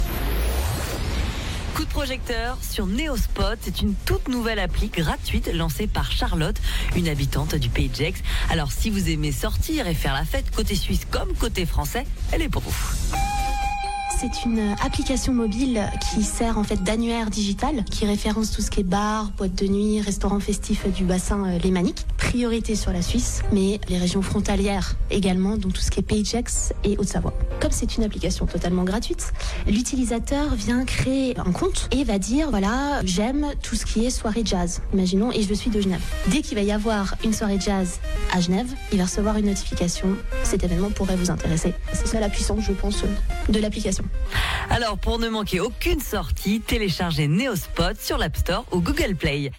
Flash Info 1